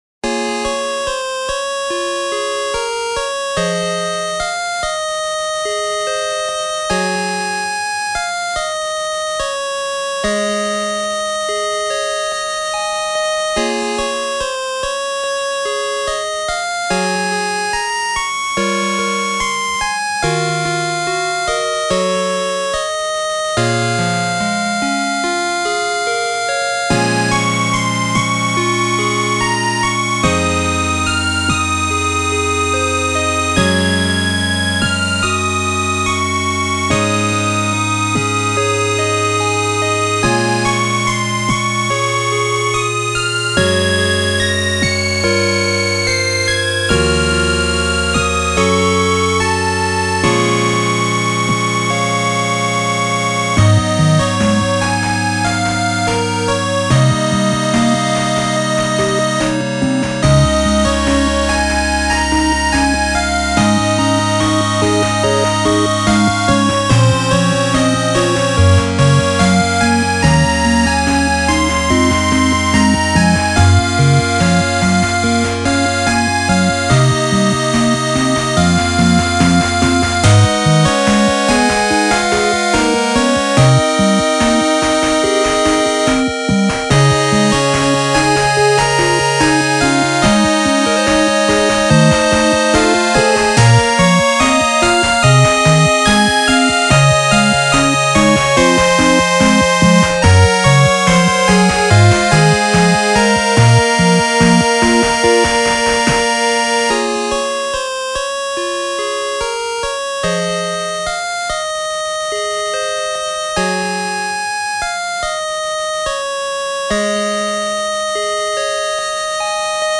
ジャンルファミコン風、8-Bit
BPM７２
使用楽器8-Bit音源
原曲は荒廃した世界をテーマにしていますが、8-Bitになると”夕暮れ時の帰り道”というイメージに近いですね。